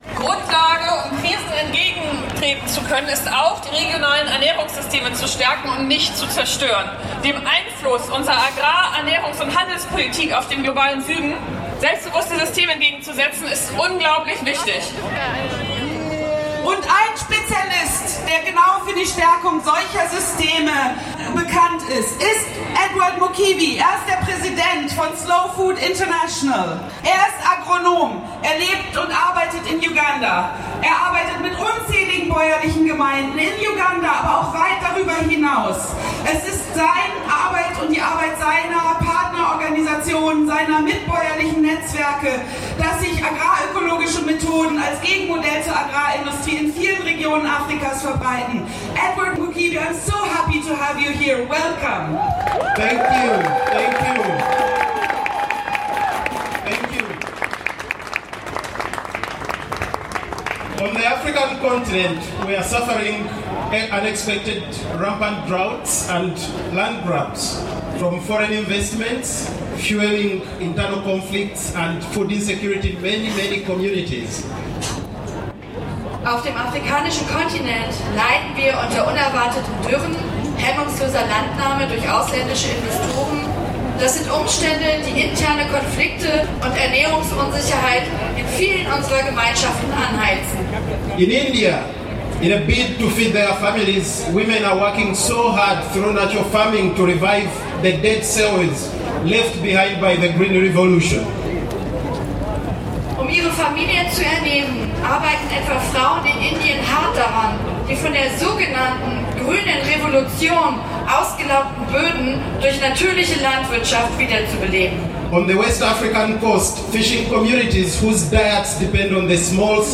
Abschlusskundgebung
Der zweite Teil des Bühnenprogramms